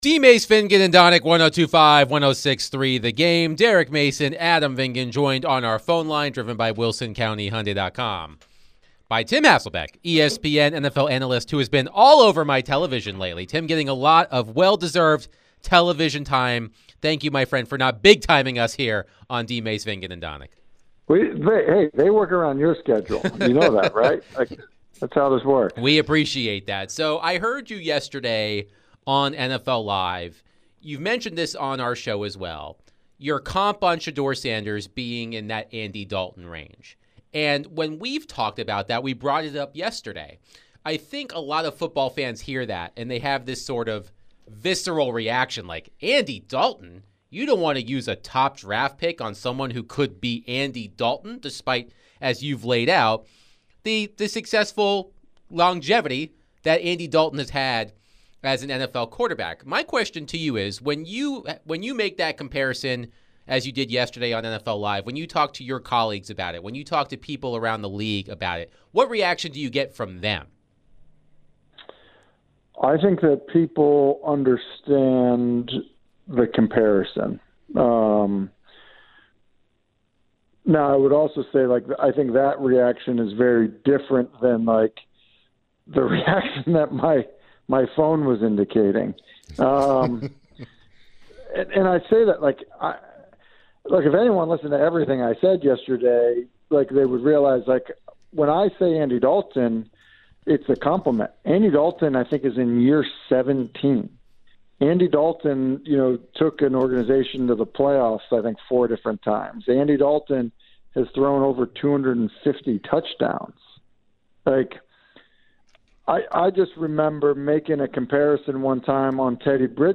ESPN NFL Analyst Tim Hasselbeck joined DVD to discuss Shedeur Sanders draft stock, Titans and more